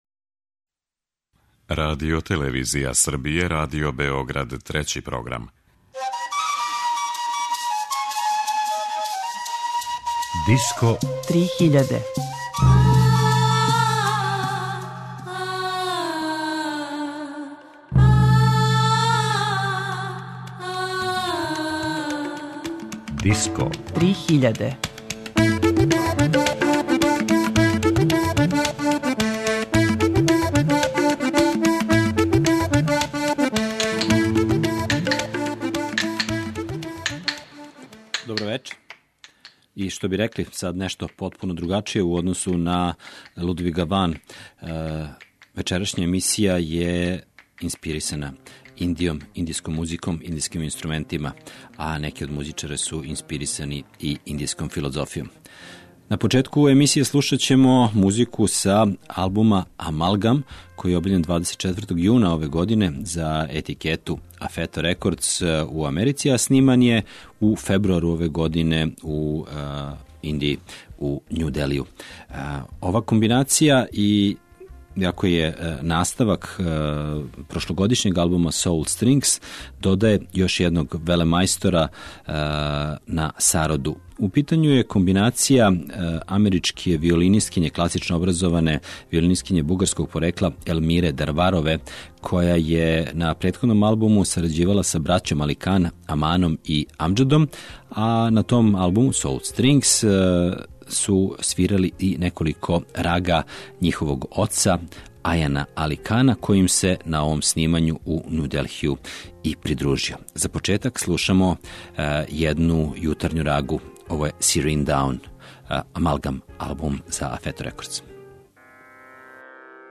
Disco 3000 је емисија посвећена world music сцени, новитетима, трендовима, фестивалима и новим албумима.